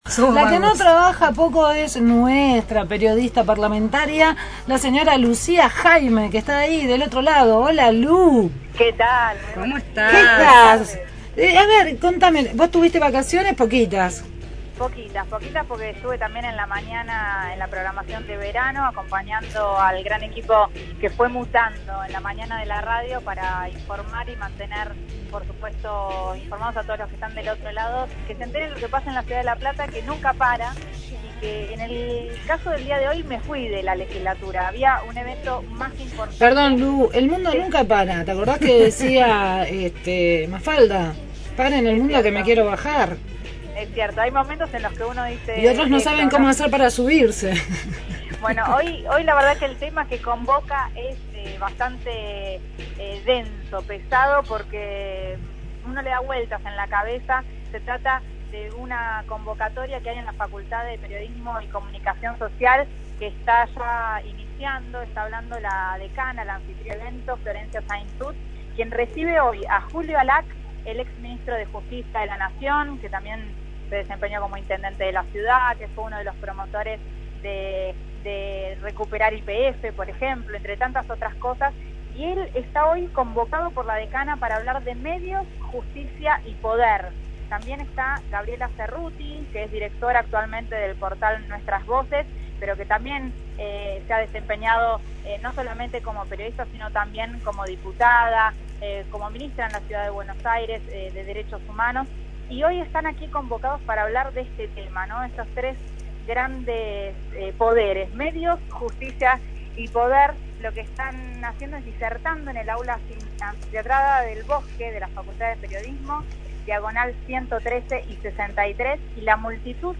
desde la Facultad de Periodismo con una charla sobre Medios, Justicia y Poder